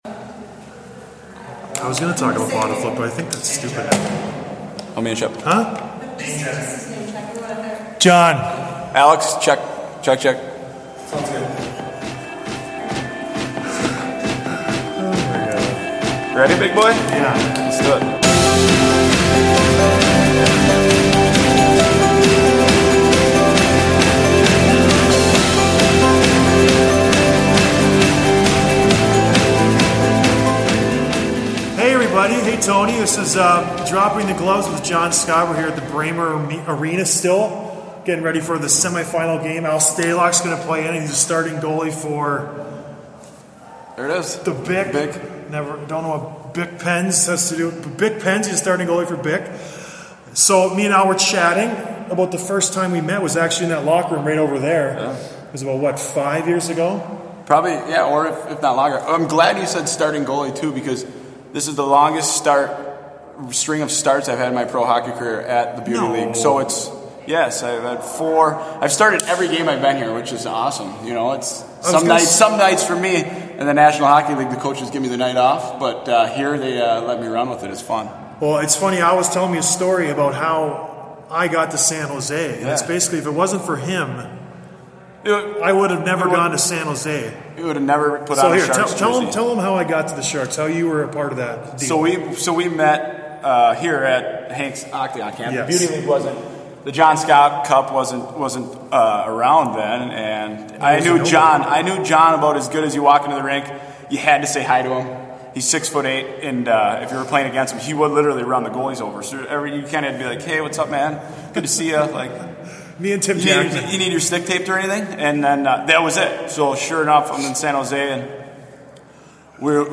Interview with Alex Stalock from San Jose